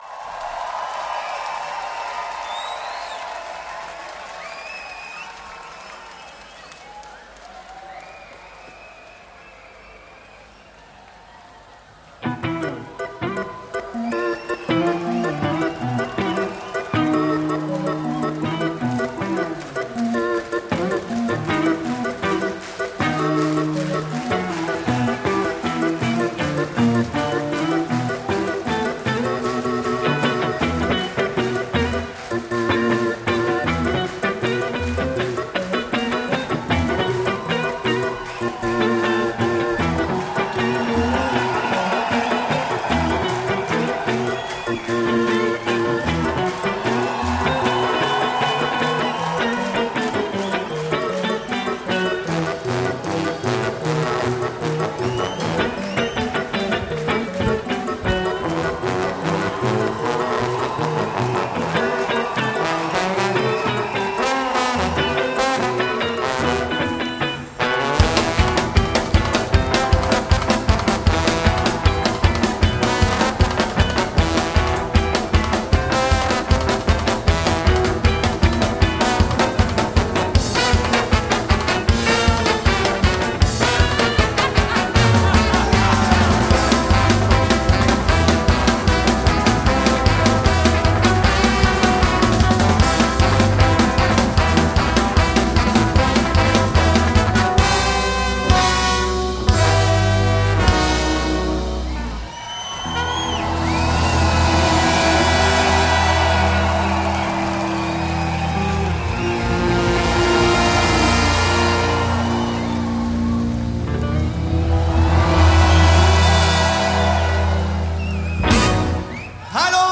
genre mashup and general haywire